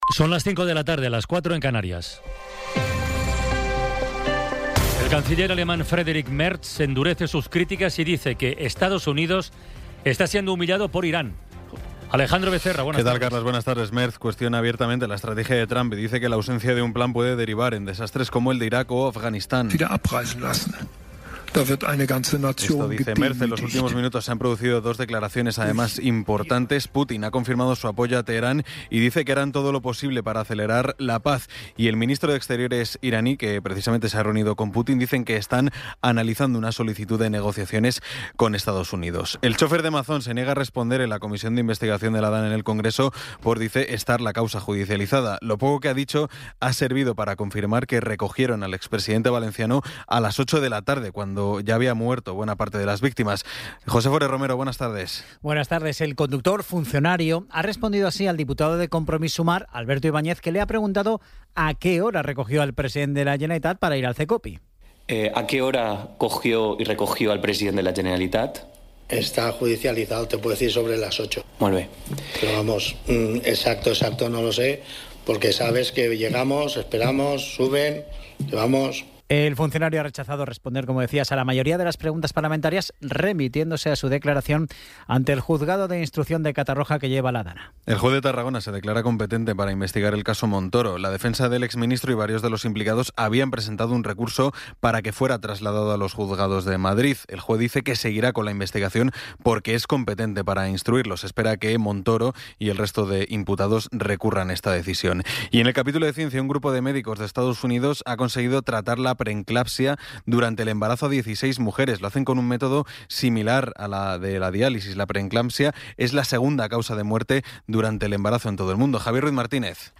Resumen informativo con las noticias más destacadas del 27 de abril de 2026 a las cinco de la tarde.